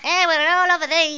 Amiga 8-bit Sampled Voice
excellent.mp3